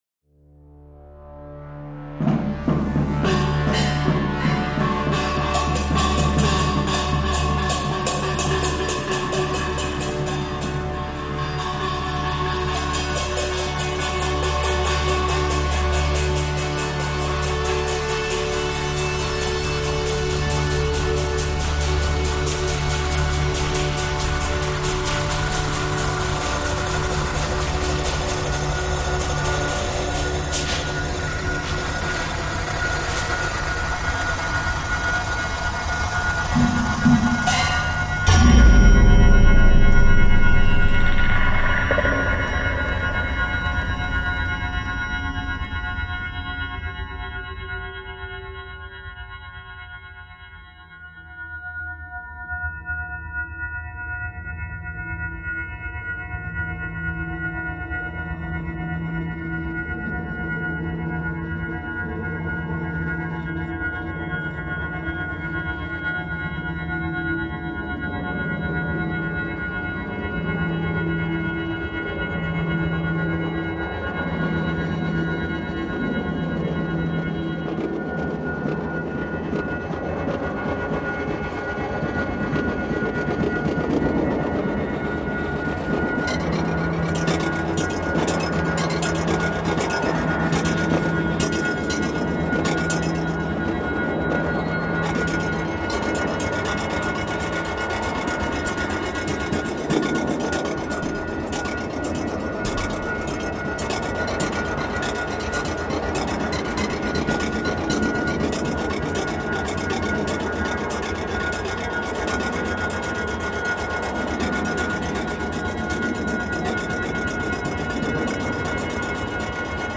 Beijing temple music reimagined